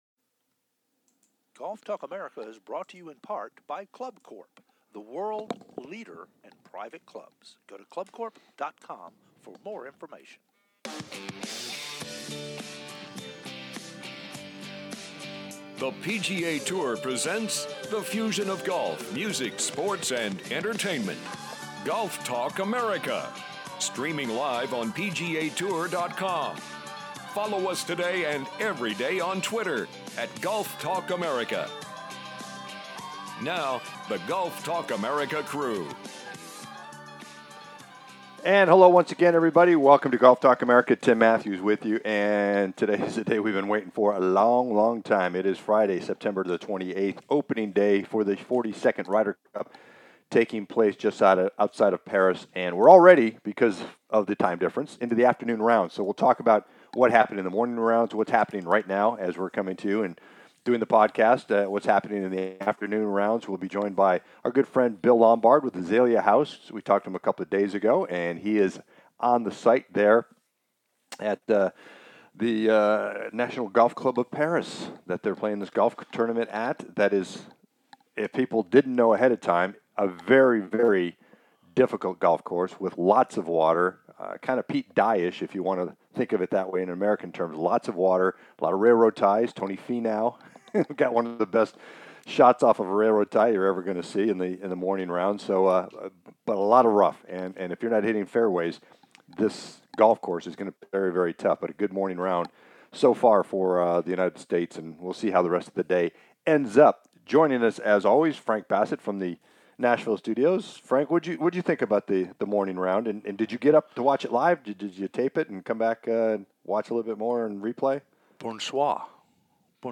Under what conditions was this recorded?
"LIVE" From The Ryder Cup